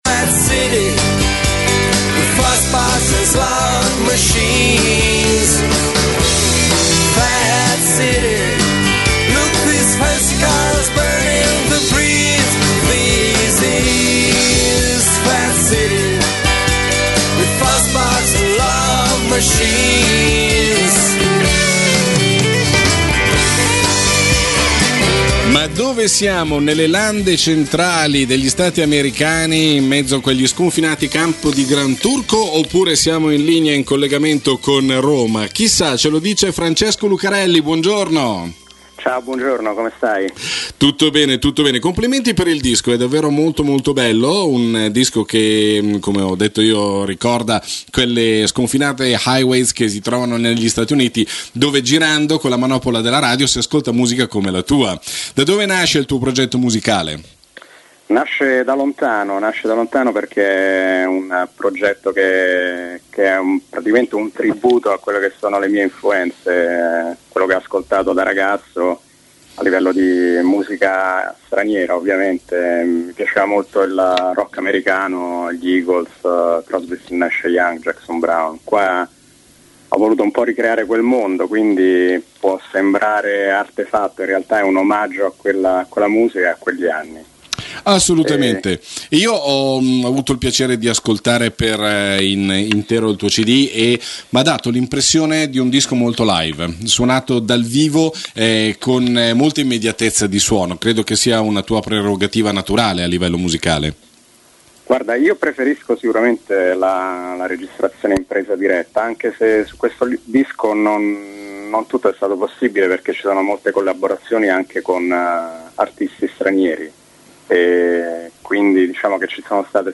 Interview on Radio Cernusco Stereo, Italy.